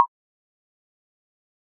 confirm.ogg